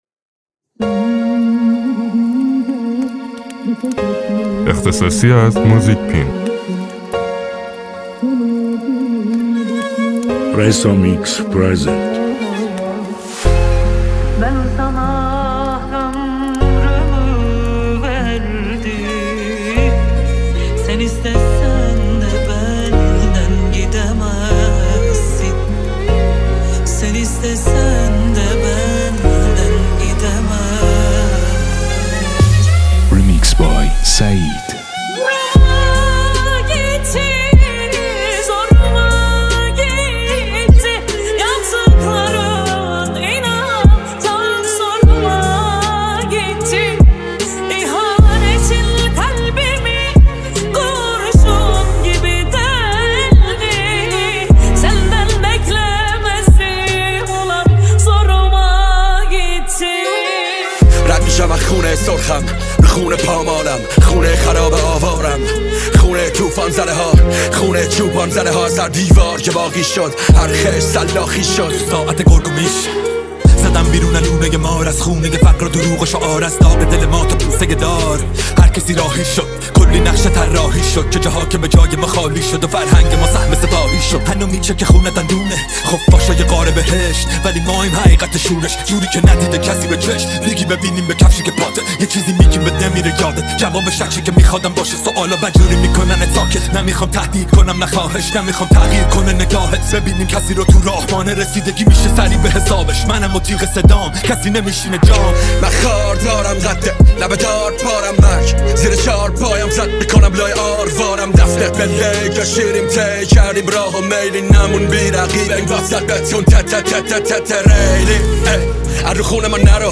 Remix Rapi